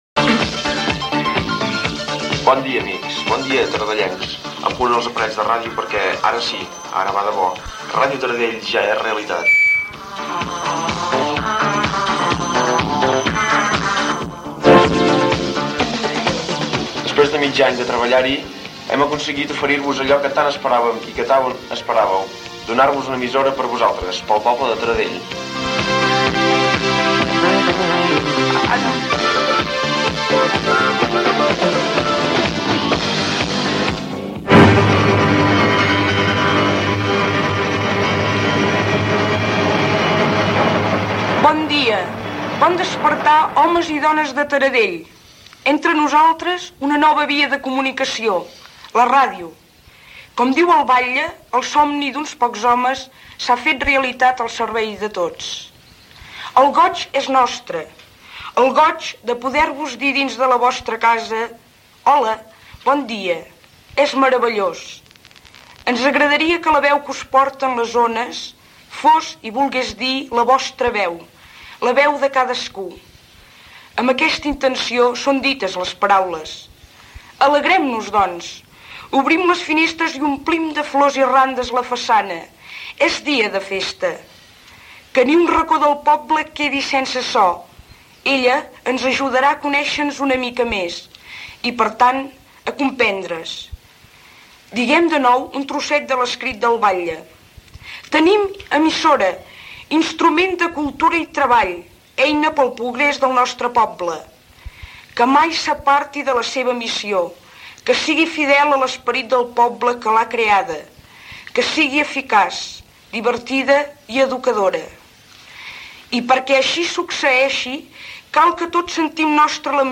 Emissió inaugural, salutació inicial fent servir paraules del batlle de Taradell, programació, actes a Taradell per Sant Jordi, programació, telèfon.
FM